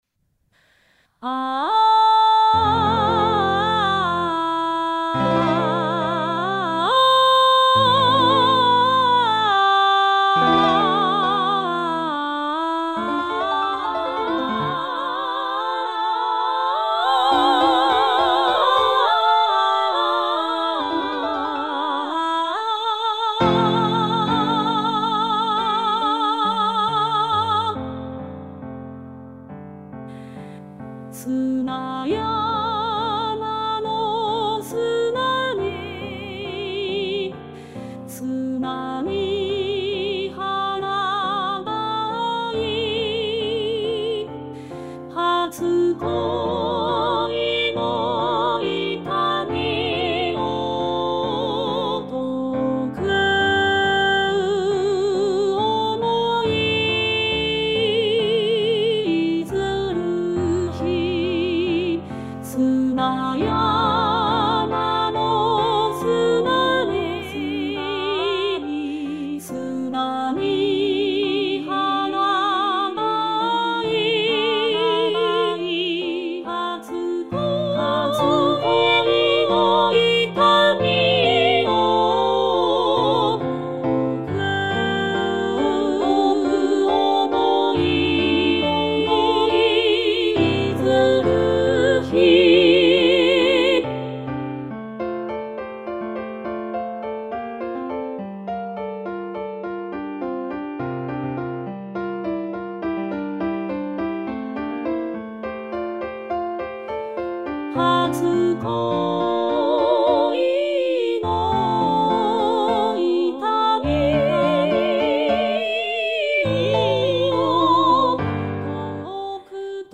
hatsukoi-female3.mp3